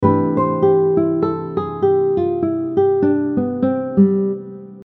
The first 3 application examples are practical melodic lines using the Major 6 diminished scale over a C Major chord.
Major-6-diminished-scale-example-1.mp3